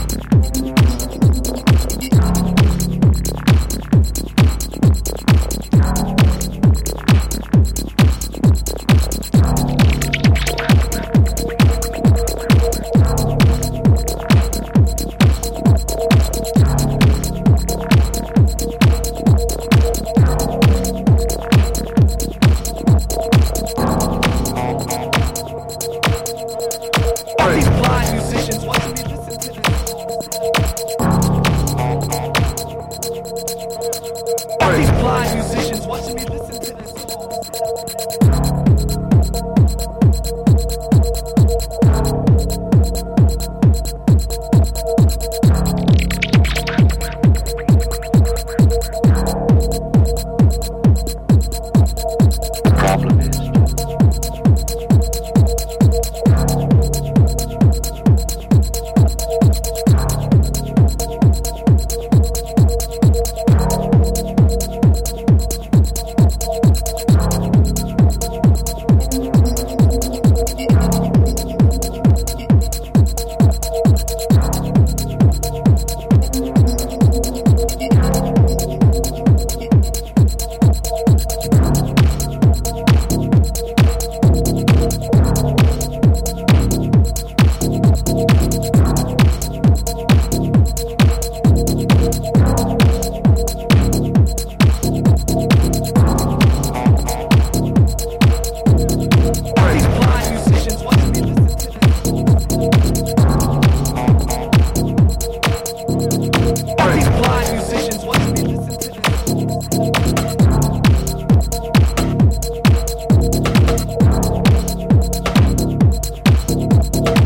exploring raw analog and techno-driven soundscapes.